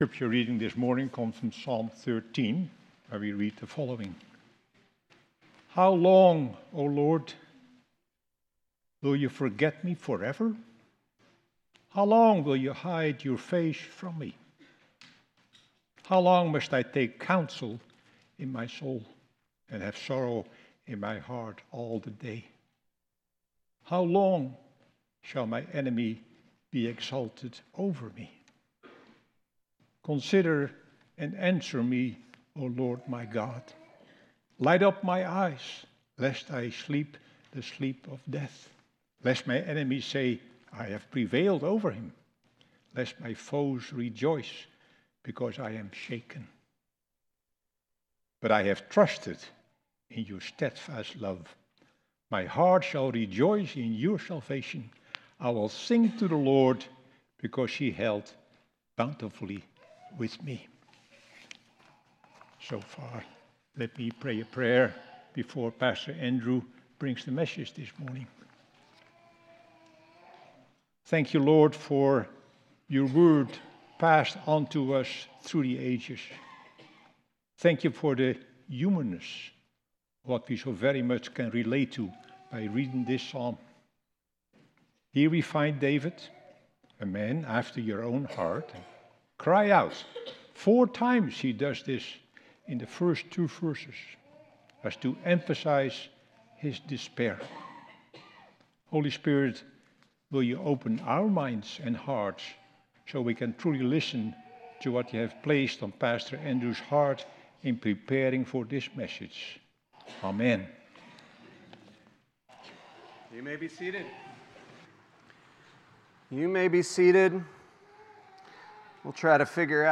6.15.25 Sermon.m4a